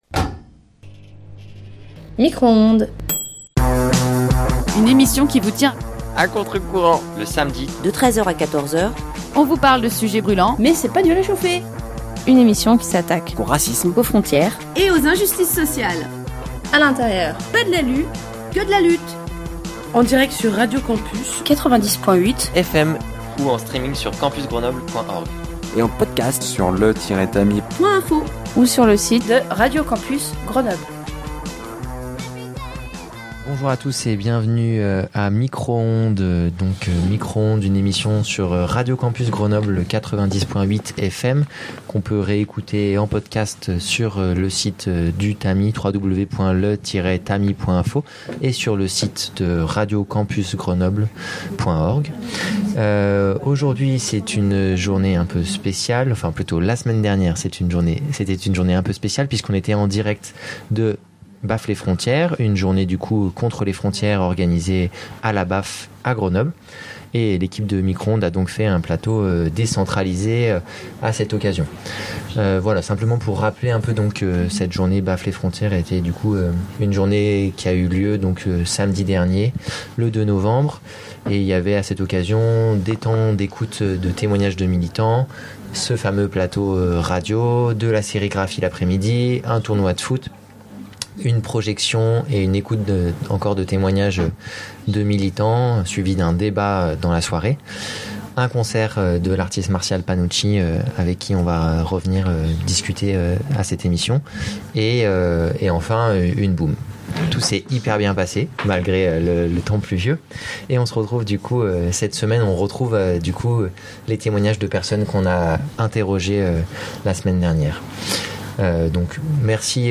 Micro-Ondes est une émission de radio diffusée tous les samedis de 13h à 14h, sur Radio Campus Grenoble (90.8FM).
Au programme de cette émission : deux émissions spéciales en direct de la Baf à l’occasion de la journée Baffe les frontières !